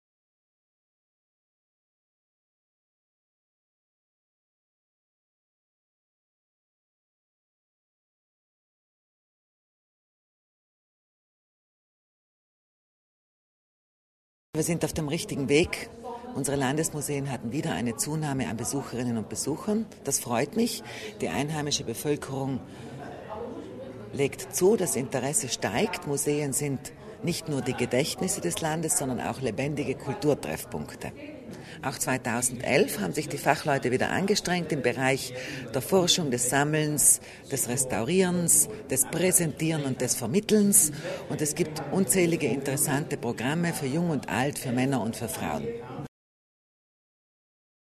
LPA - Die Besucherzahlen der vergangenen Saison sowie die geplanten Ausstellungen und Neuheiten der neun Südtiroler Landesmuseen für das laufende Jahr 2011 hat heute (28. Jänner) Kulturlandesrätin Sabina Kasslatter Mur auf einer Pressekonferenz in Bozen vorgestellt.